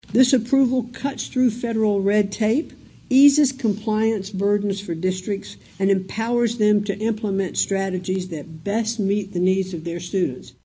MCMAHON AND GOVERNOR REYNOLDS MADE THEIR COMMENTS AFTER TOURING AN ELEMENTARY SCHOOL IN DENISON AND VISITING WITH STUDENTS.